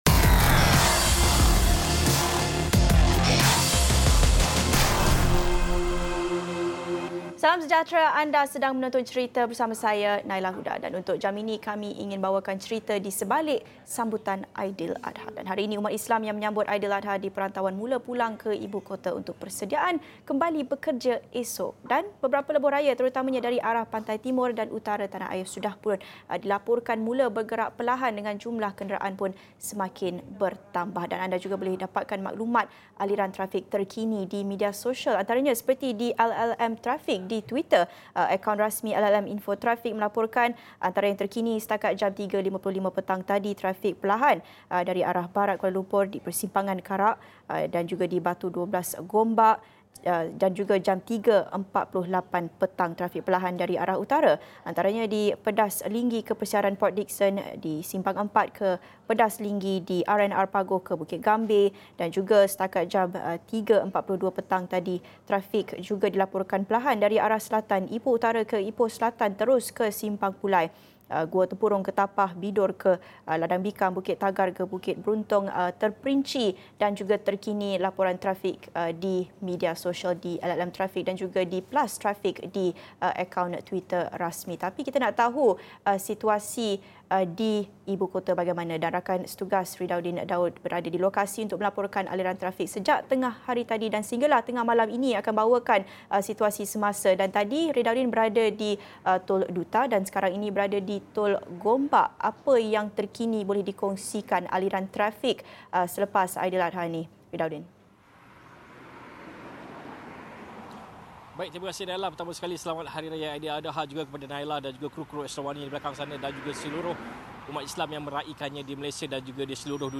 Wartawan Astro AWANI menceritakan kisah di sebalik sesuatu berita yang dilaporkan.